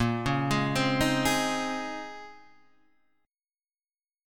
A#m11 chord